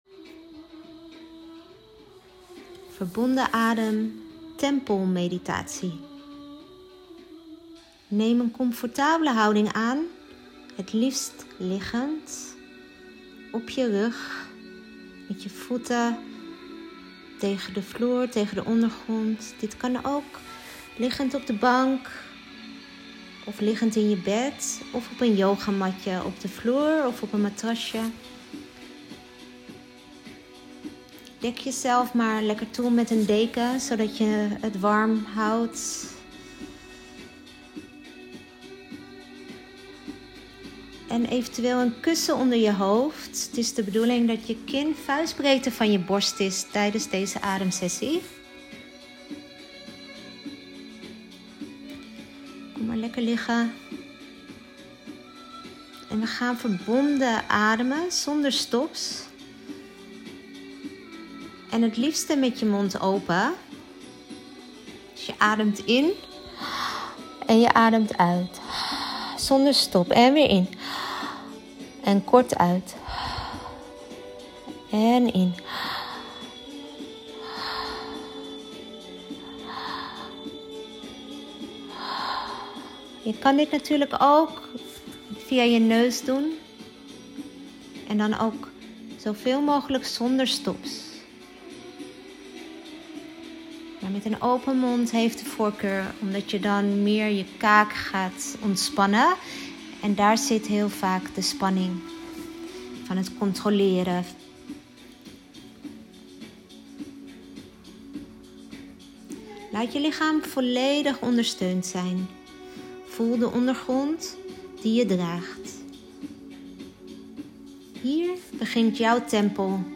Het enige wat je hoeft te doen, is mijn stem volgen en je overgeven aan de meditatie of ademhalingsoefening.